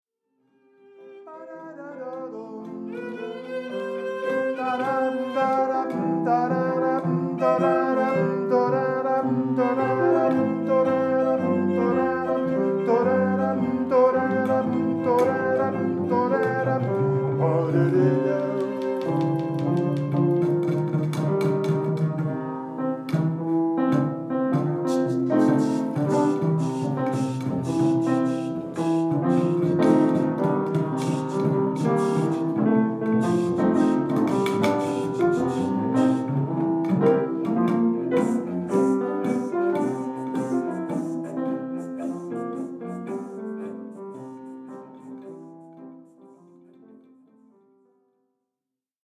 Deze improvisaties zijn in principe tonaal, maar kunnen muzikaal alle richtingen opgaan, onafhankelijk van genre en stijl.
little-impro-demo.mp3